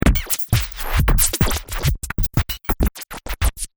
描述：电音室循环划痕
Tag: 130 bpm Electronic Loops Scratch Loops 636.70 KB wav Key : Unknown